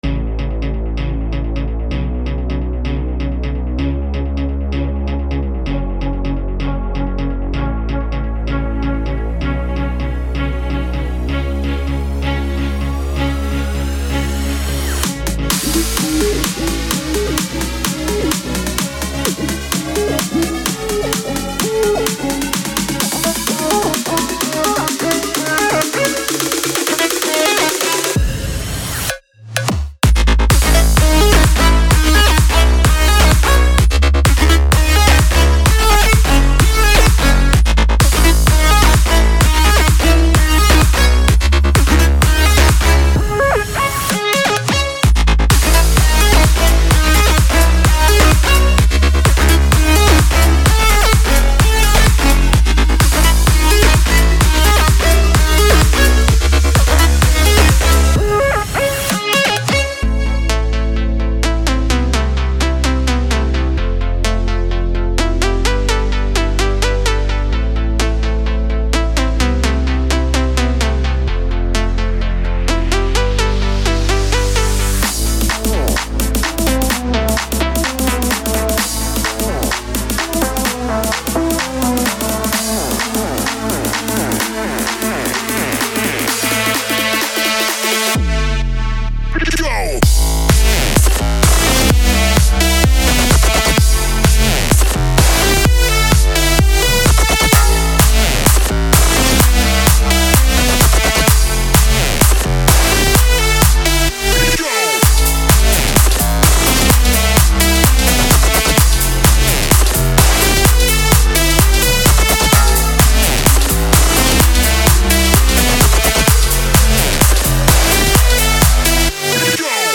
• 46 Melody Loops WAV & MIDI
• 37 Drum Loops